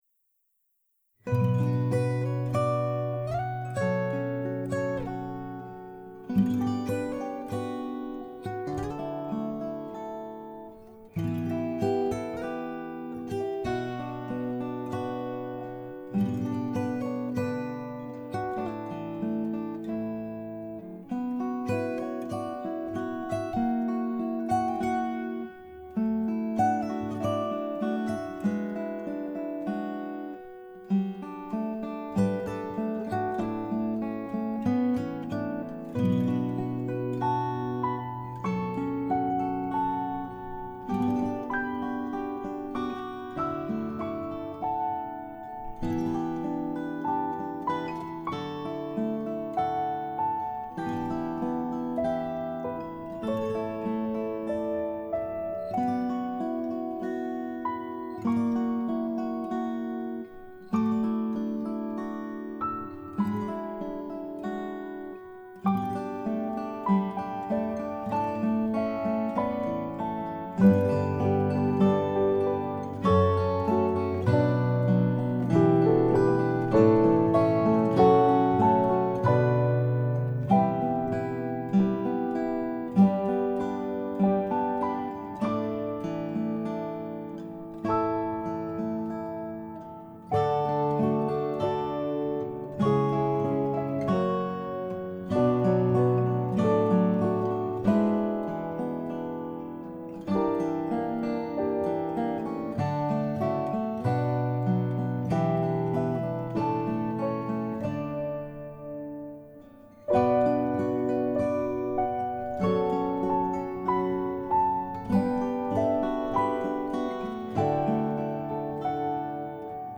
In Every Smile Piano Guitar 2019